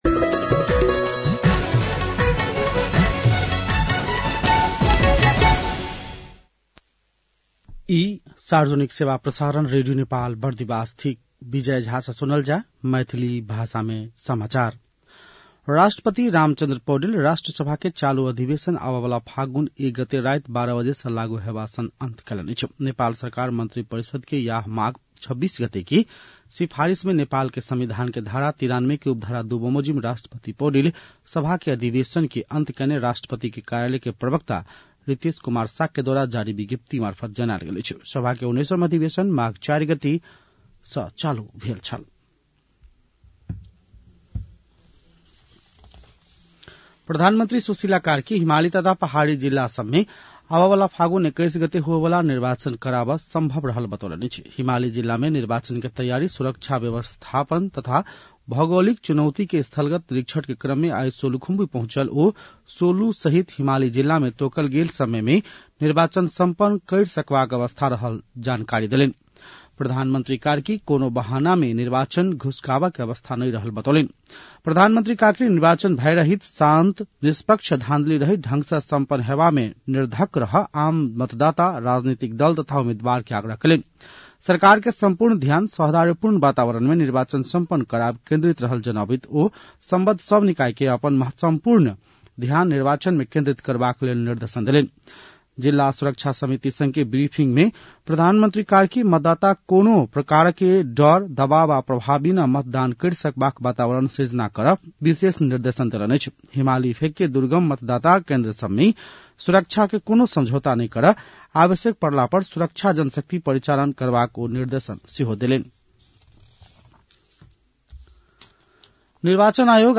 मैथिली भाषामा समाचार : २९ माघ , २०८२
6.-pm-maithali-news-1-3.mp3